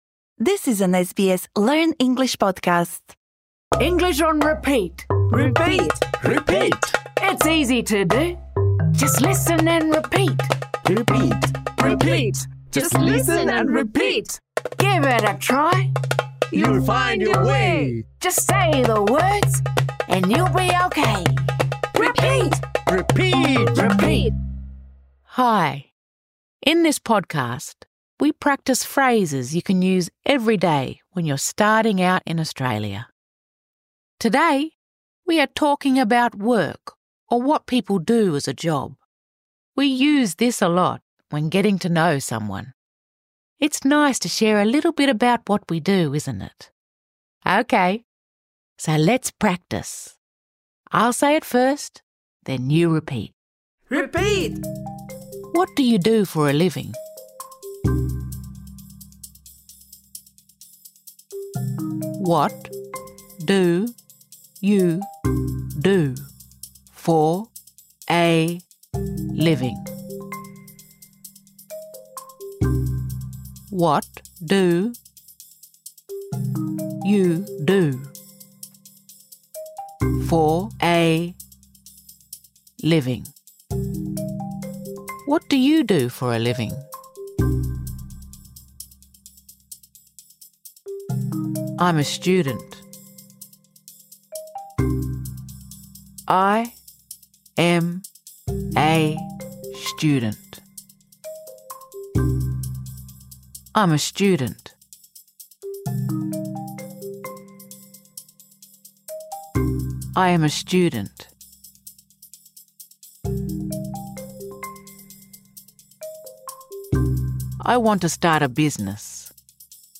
This lesson is designed for easy-level learners. In this episode, we practise saying the following phrases What do you do for a living? I’m a student. I want to start a business. I work part-time.